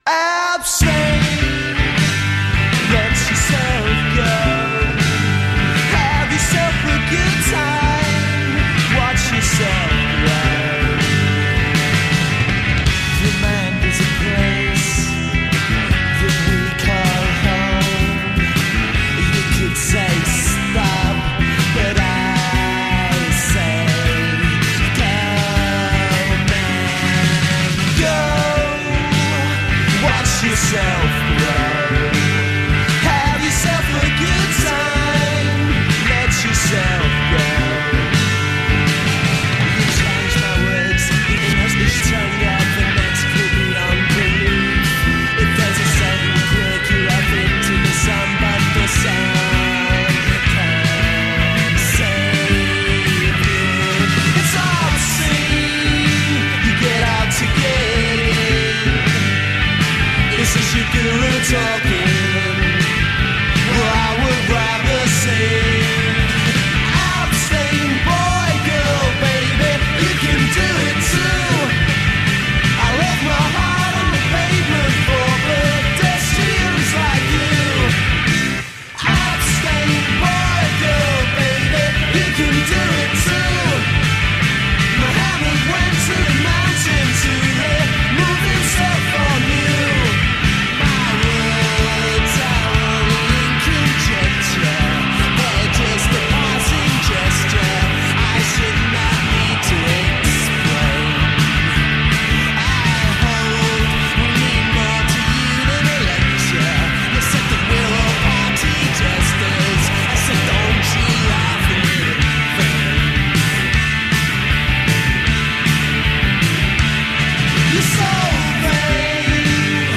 Indie rock
vocals, bass
vocals, guitar
drums
were a three-piece rock band from London, England